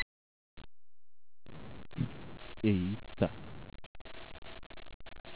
Recording WAVE 'opa.wav' : Unsigned 8 bit, Rate 8000 Hz, Mono
Tudo bem, com 8 bits de resolução e uma taxa de amostragem de 8kHz não dá pra esperar muita coisa.